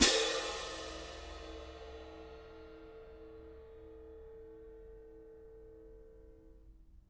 Percussion
cymbal-crash1_mp_rr1.wav